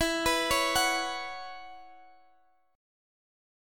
Bsus2/E chord